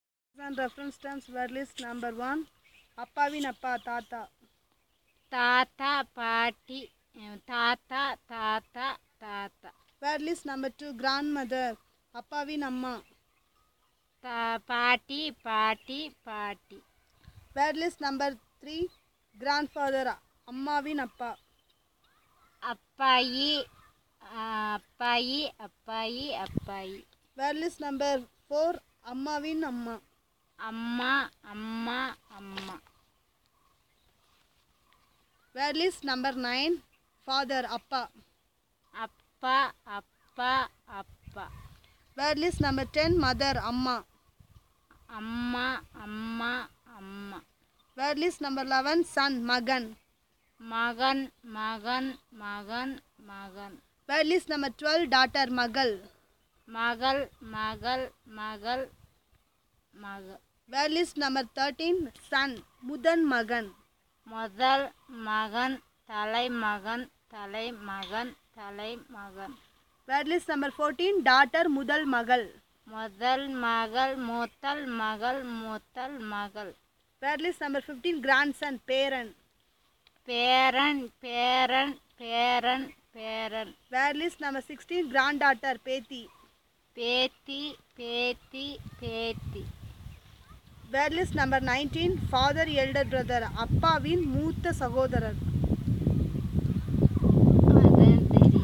Elicitation of words for kinship terms - Part 1